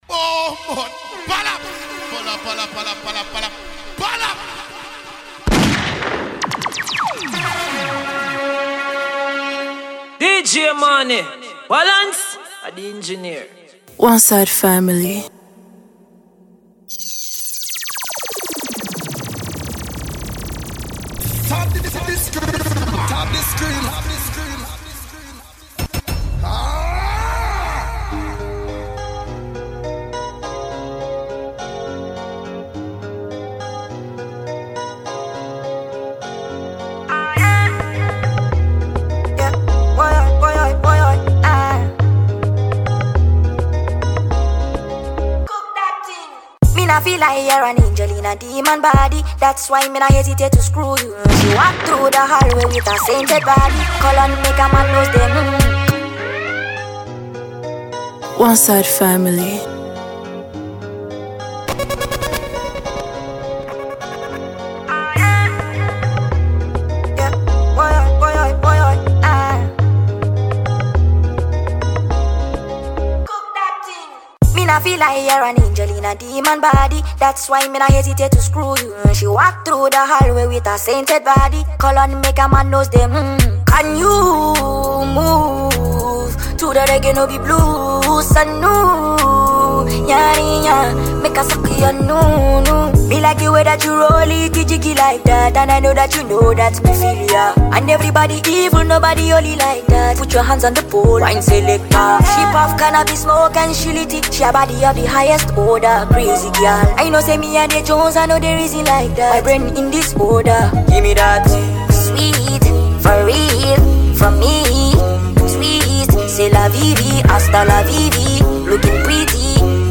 a Ghanaian Disc Jockey is back with a new DJ mixtape